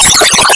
Type: Interview
0kbps Stereo